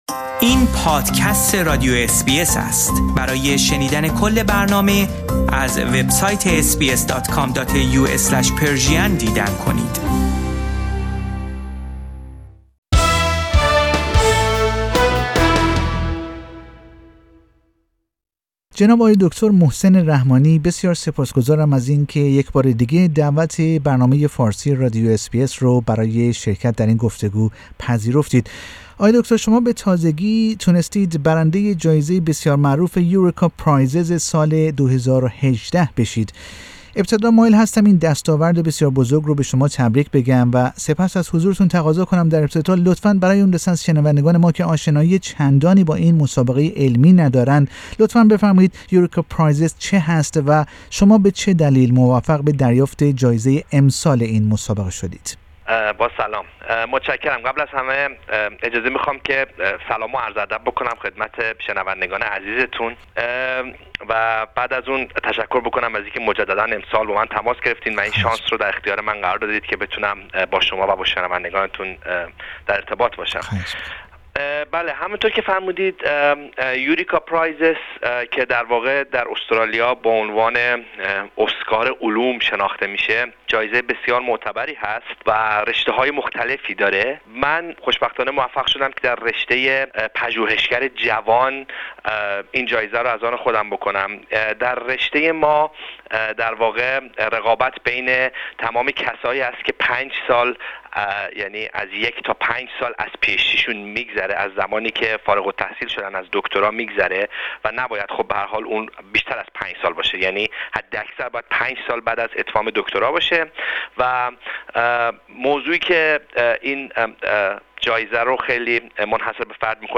در گفتگو با اس بی اس فارسی